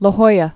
(lə hoiə)